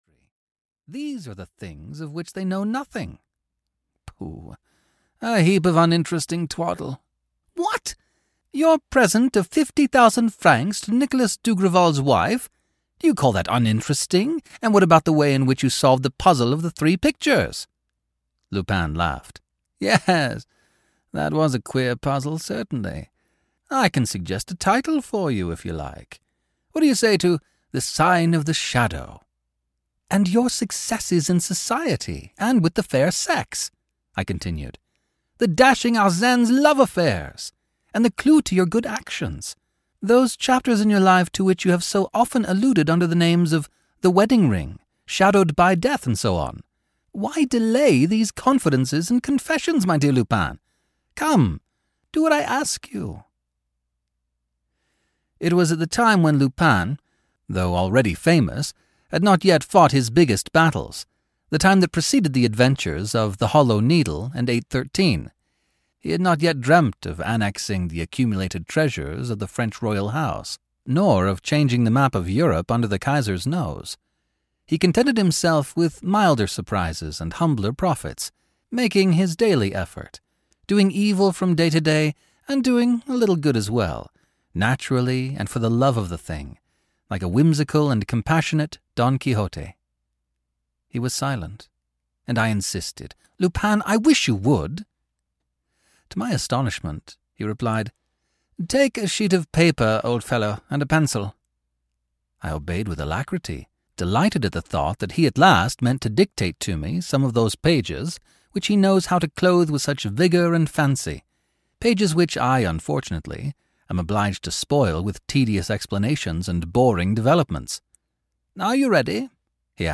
From The Confessions of Arsene Lupin (EN) audiokniha
Ukázka z knihy